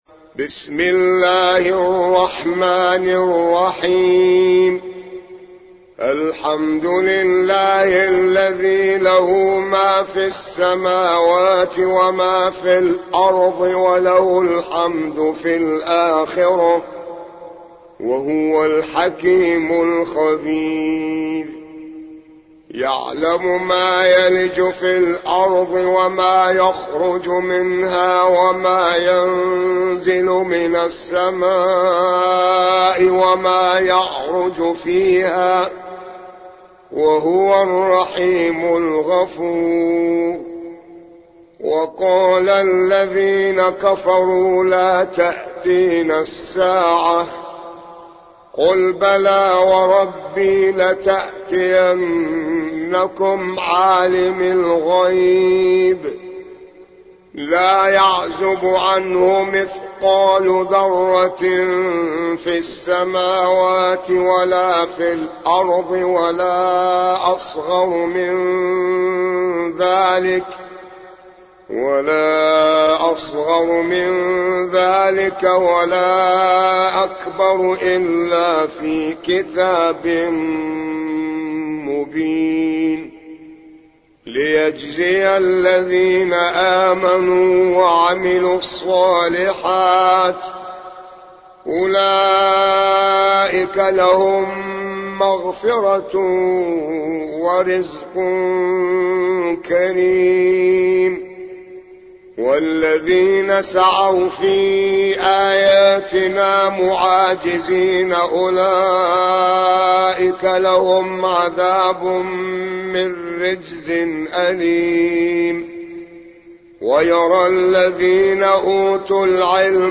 34. سورة سبأ / القارئ